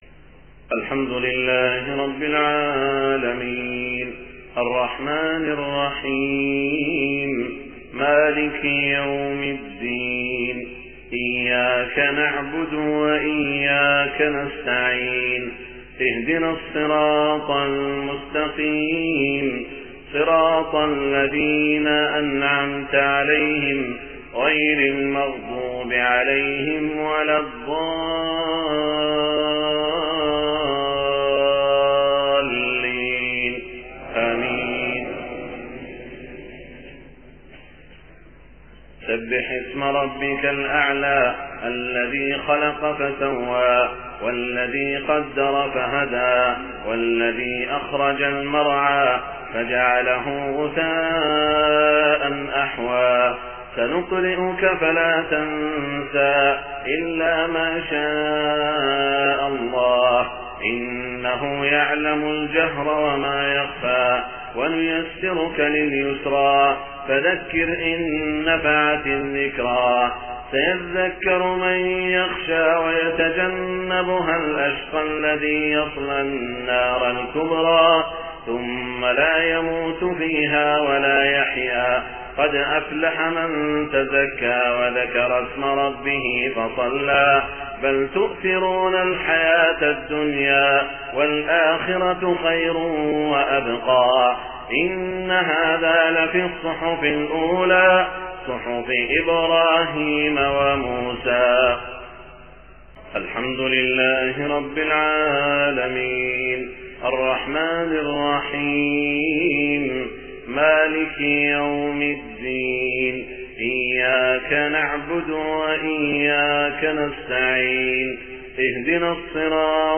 صلاة الجمعة 3 محرم 1429هـ سورتي الأعلى و الغاشية > 1429 🕋 > الفروض - تلاوات الحرمين